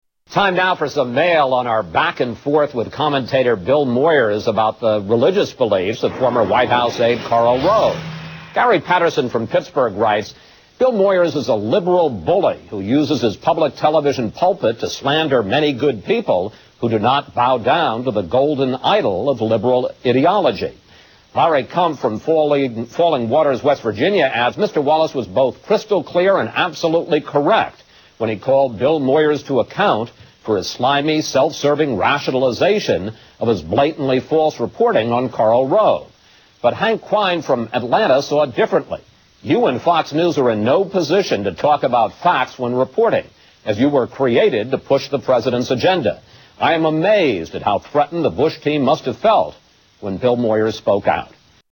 Tags: Media Chris Wallace FOX News Sunday Newscaster Fox News